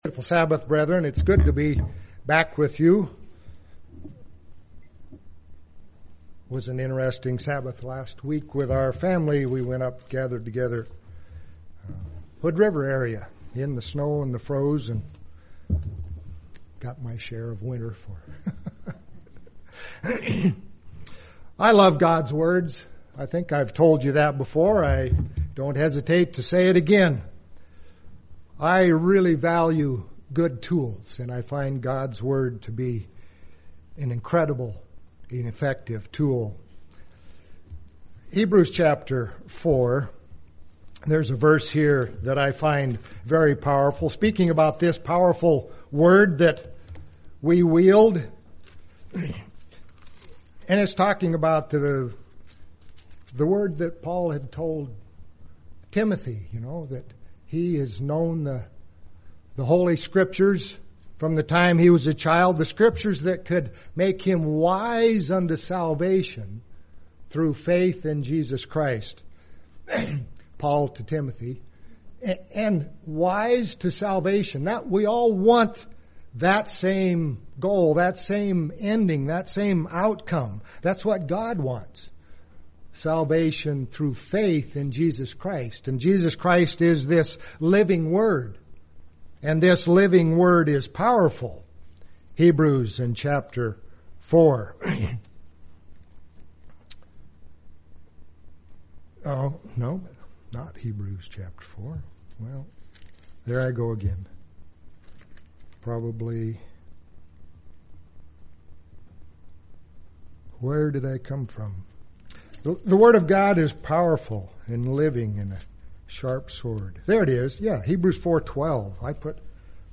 This sermon takes a closer look at Proverbs chapter 11.